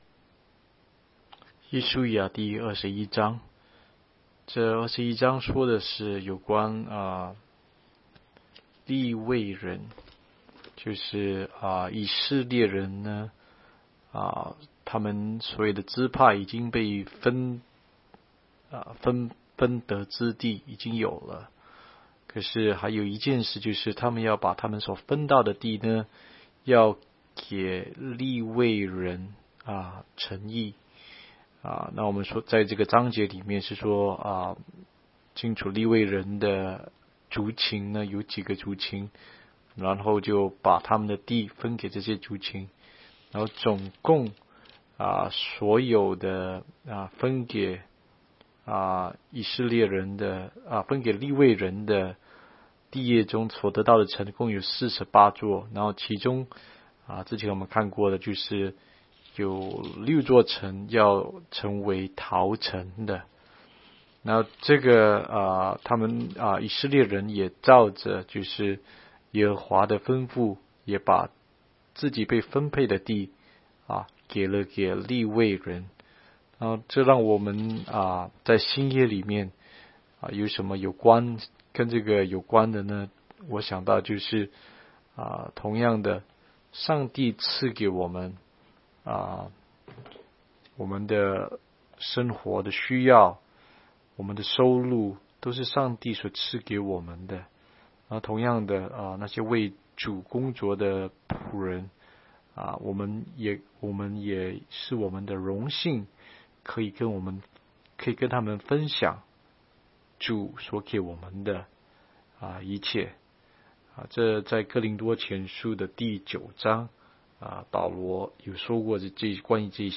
16街讲道录音 - 每日读经-《约书亚记》21章